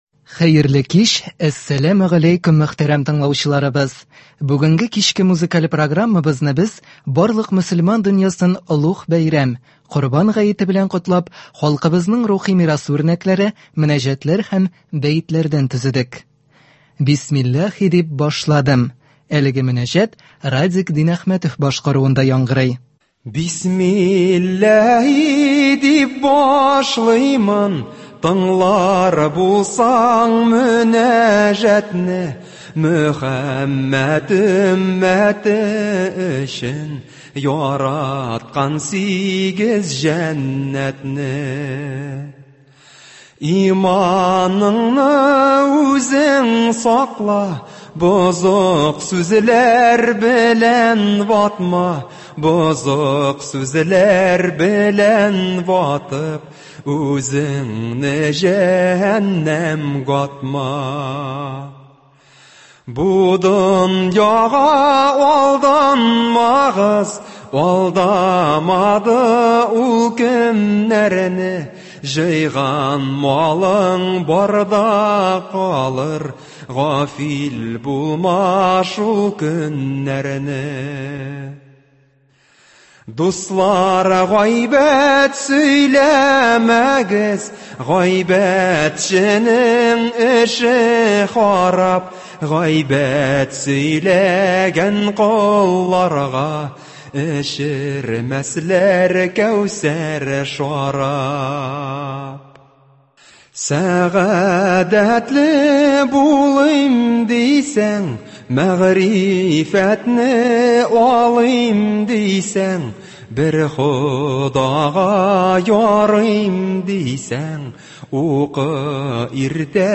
Бәйрәм концерты. Бәетләр һәм мөнәҗәтләр.